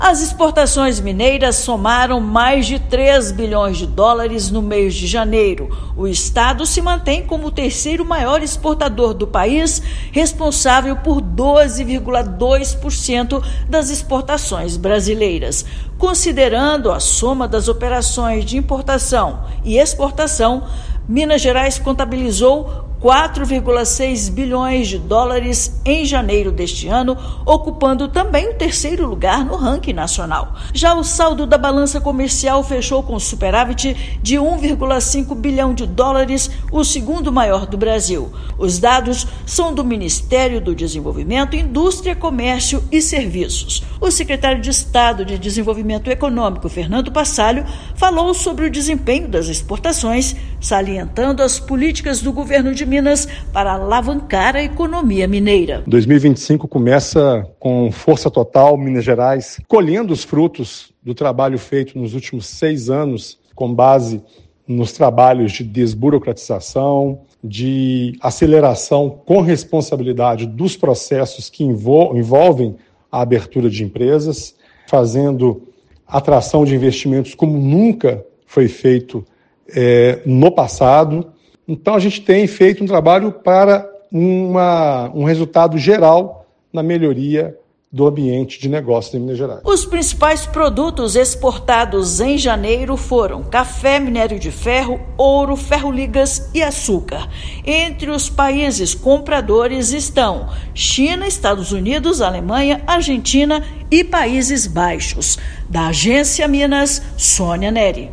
Potencial do estado no comércio exterior é reforçado pela quantidade de países alcançados; somente em janeiro, foram 159 mercados. Ouça matéria de rádio.